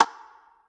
spinwheel_tick_03.ogg